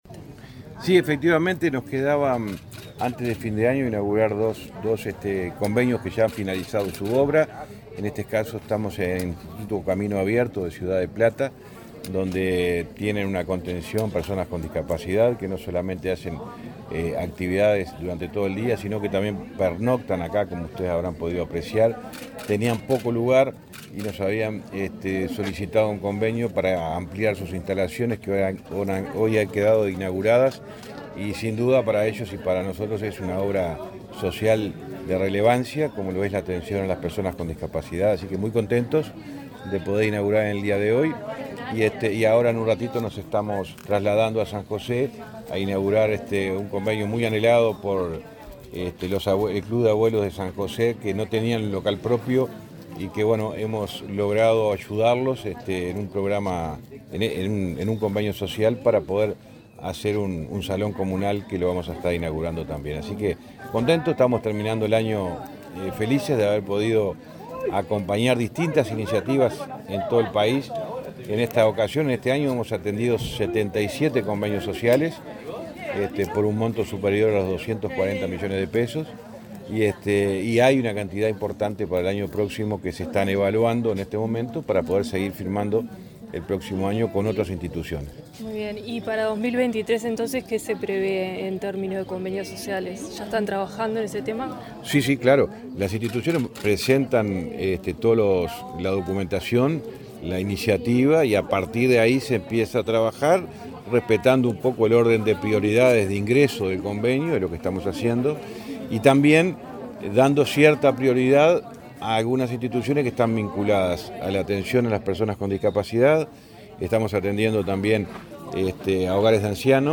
Entrevista al ministro de Transporte, José Luis Falero
El ministro de Transporte, José Luis Falero, dialogó con Comunicación Presidencial el lunes 26, durante su recorrida por el departamento de San José,